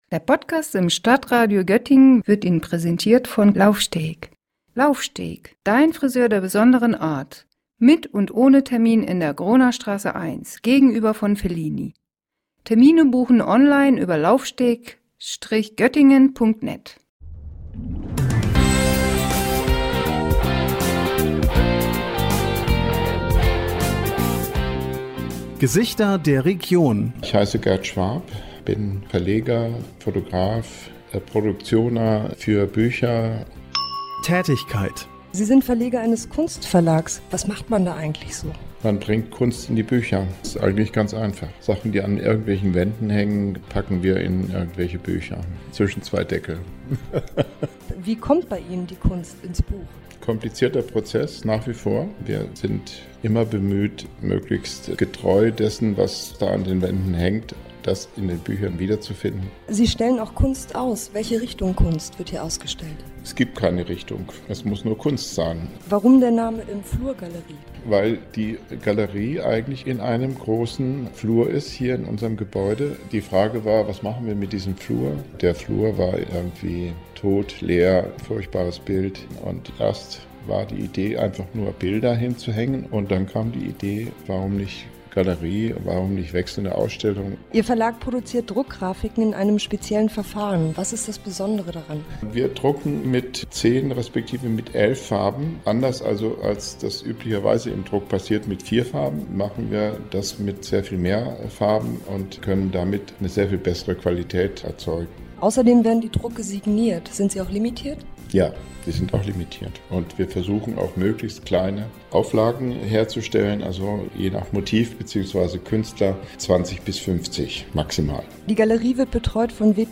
Für jemanden, der aus Bayern im Jahr 1968 nach Göttingen zum Studieren kommt, scheint der zukünftige Weg bereits etwas vorbestimmt zu sein.